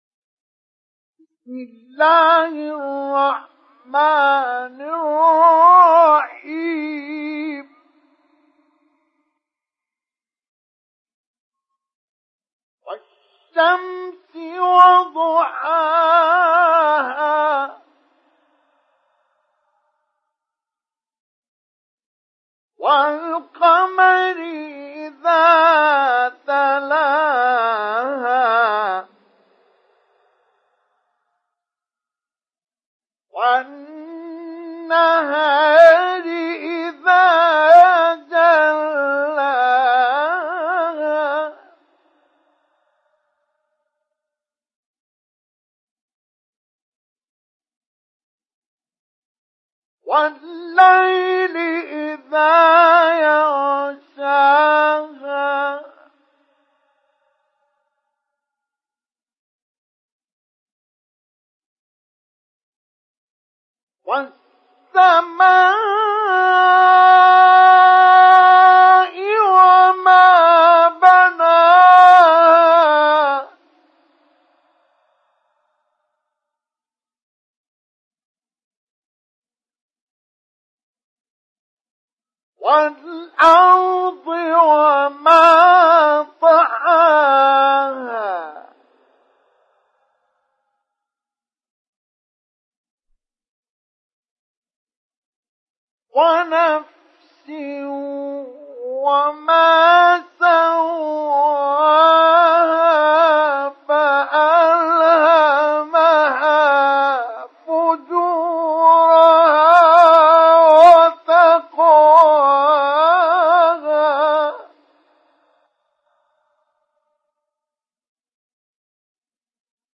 Surah Ash Shams Download mp3 Mustafa Ismail Mujawwad Riwayat Hafs from Asim, Download Quran and listen mp3 full direct links
Download Surah Ash Shams Mustafa Ismail Mujawwad